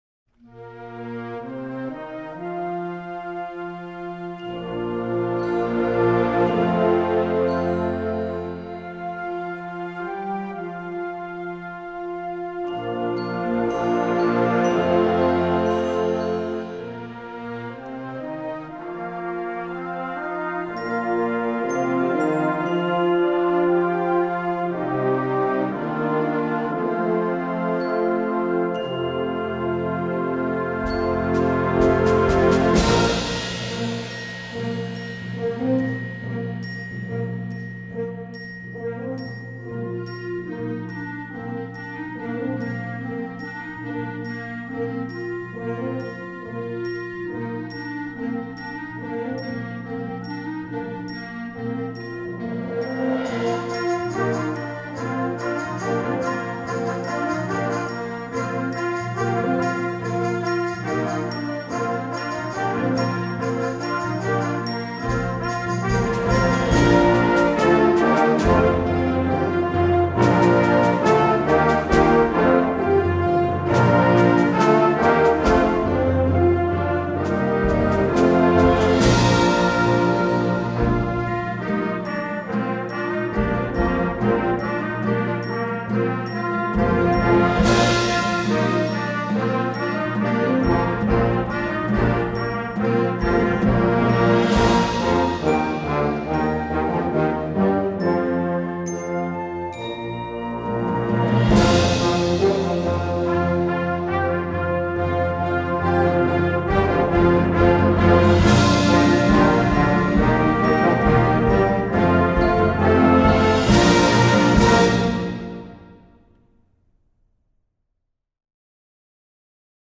Instrumentation: concert band
instructional, children